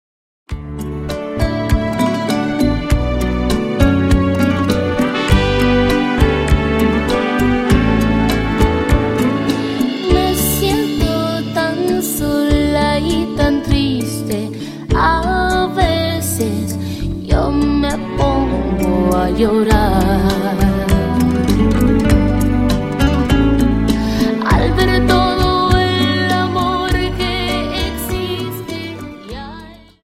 Dance: Rumba 25